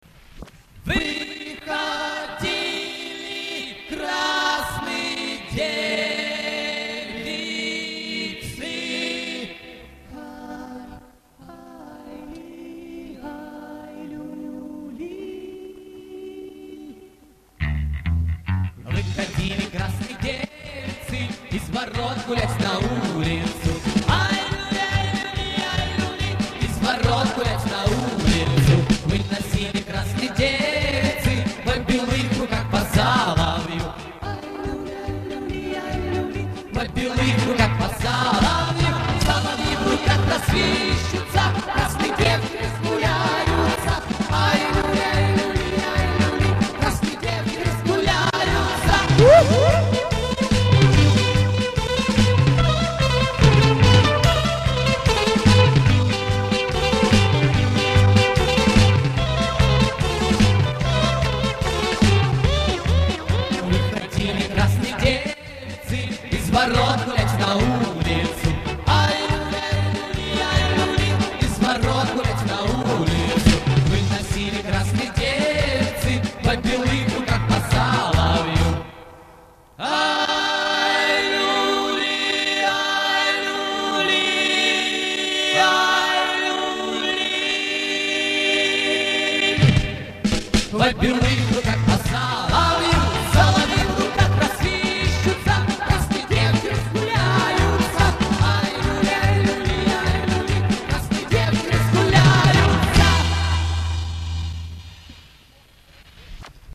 Вокально-инструментальный ансамбль
Живой звук, хорошее настроение!